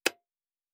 pgs/Assets/Audio/Sci-Fi Sounds/Mechanical/Device Toggle 18.wav at master
Device Toggle 18.wav